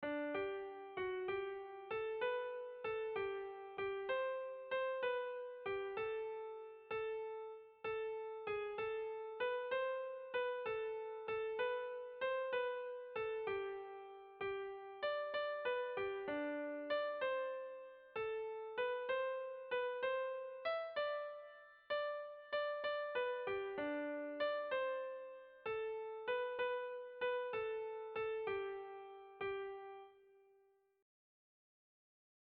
Erlijiozkoa
Pasakalle alaiaren airean egindako kantutxo xamurra.
Zortziko txikia (hg) / Lau puntuko txikia (ip)